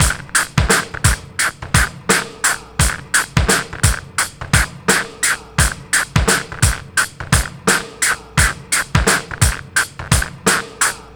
Index of /90_sSampleCDs/Best Service ProSamples vol.24 - Breakbeat [AKAI] 1CD/Partition A/TRIBEVIBE086